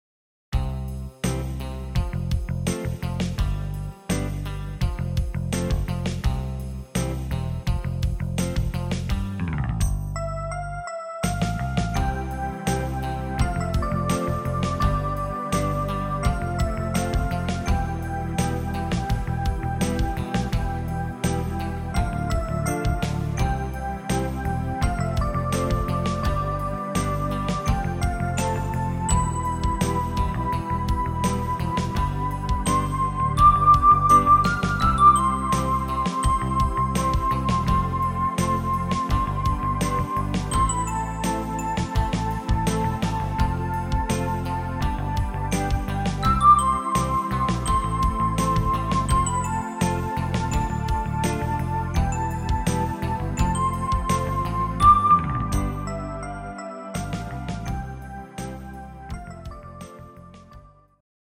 instumental Orgel & Stöhnen